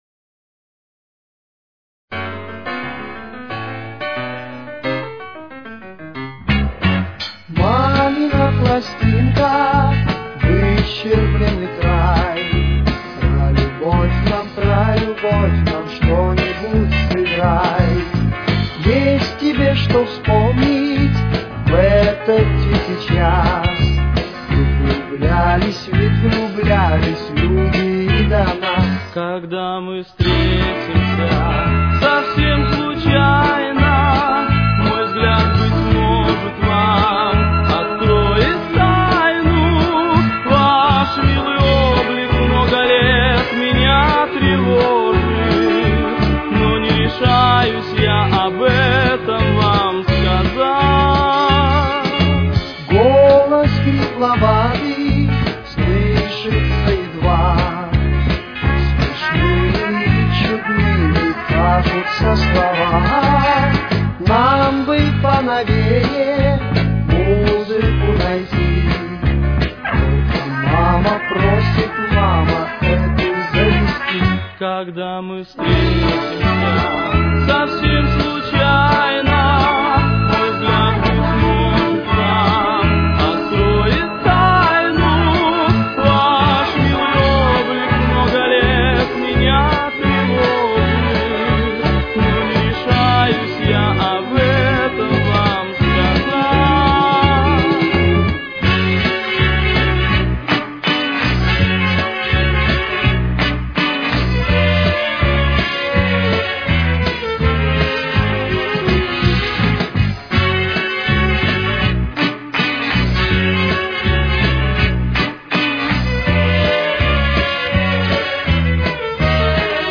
Тональность: Фа-диез минор. Темп: 86.